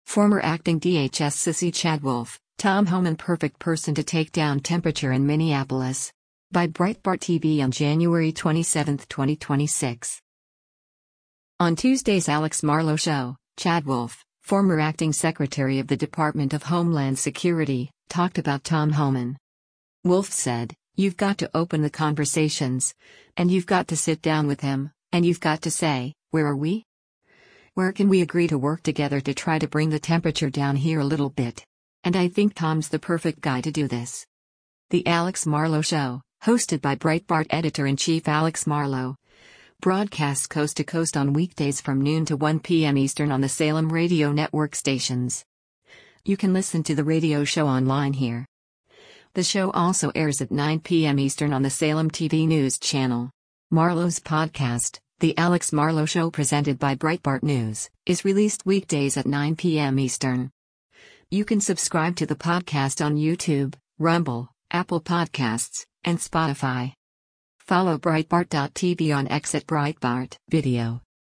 On Tuesday’s “Alex Marlow Show,” Chad Wolf, former Acting Secretary of the Department of Homeland Security, talked about Tom Homan.
The Alex Marlow Show, hosted by Breitbart Editor-in-Chief Alex Marlow, broadcasts coast to coast on weekdays from noon to 1 p.m. Eastern on the Salem Radio Network stations.